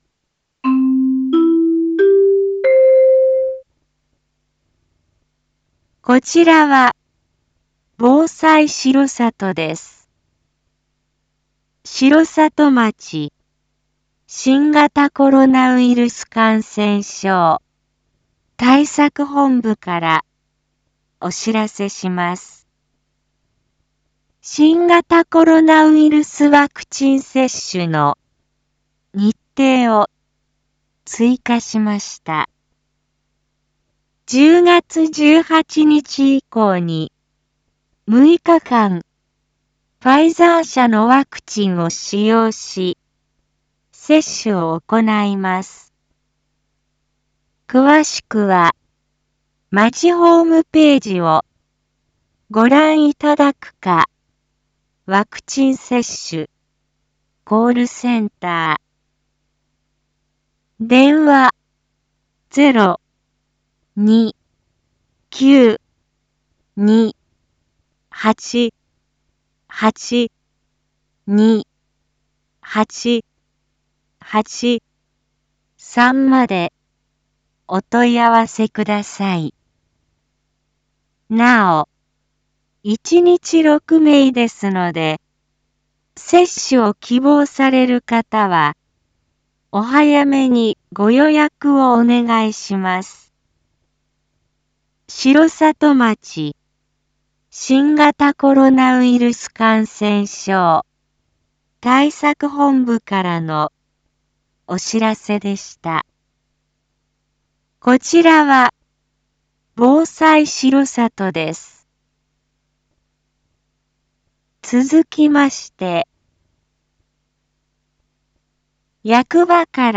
一般放送情報
Back Home 一般放送情報 音声放送 再生 一般放送情報 登録日時：2021-09-21 19:03:19 タイトル：Ｒ３．９．２１ １９時 インフォメーション：こちらは防災しろさとです。